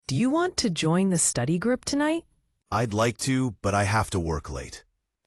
Short conversation
L-B1-004-dialogue.mp3